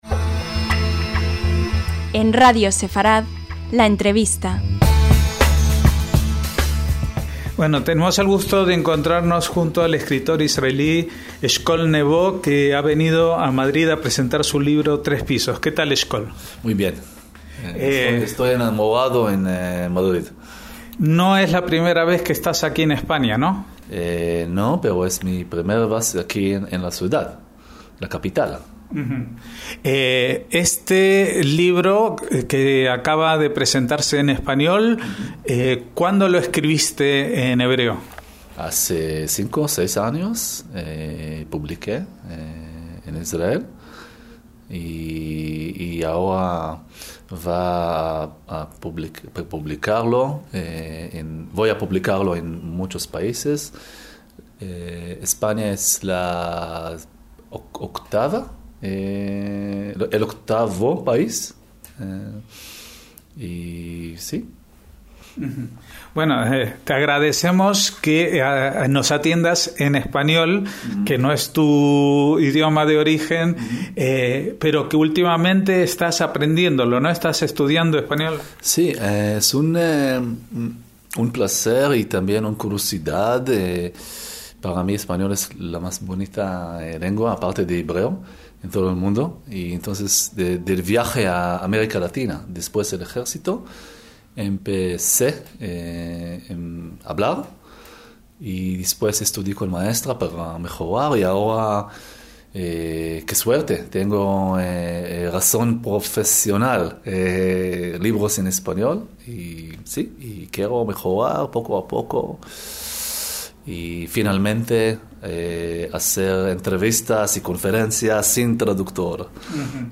LA ENTREVISTA - El escritor israelí Eshkol Nevó estuvo en Madrid para presentar la cuarta traducción de una de sus novelas, "Tres pisos", y tuvo a bien atendernos en un español casi perfecto.